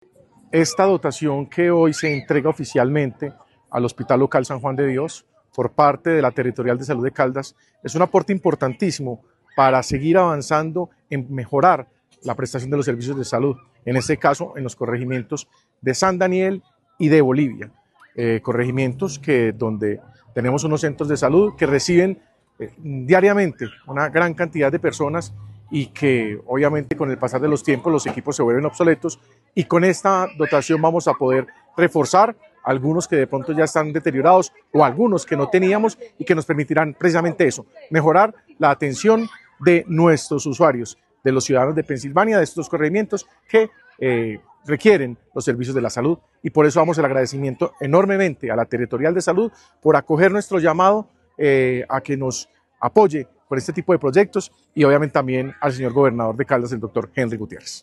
Jesús Iván Ospina, alcalde de Pensilvania.
AUDIO-ALCALDE-PENSILVANIA.mp3